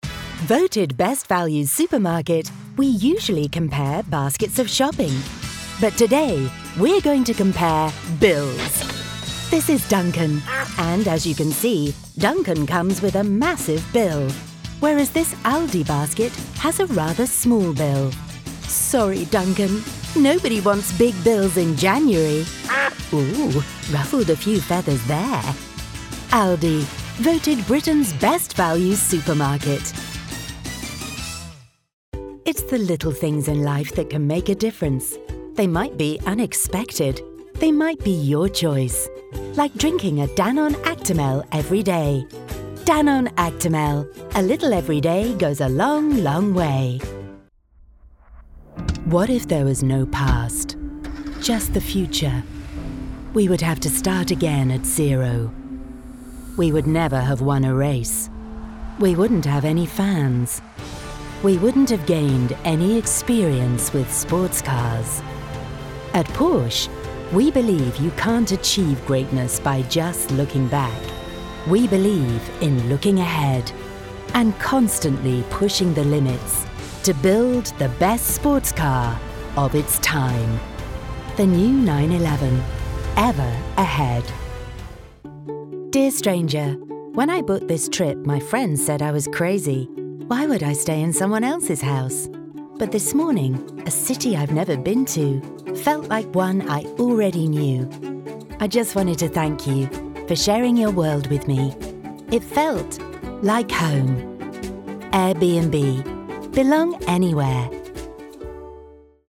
Female
English (British)
Yng Adult (18-29), Adult (30-50)
I have a warm, silky, believable, confident, professional voice.
Main Demo
Commercial Reel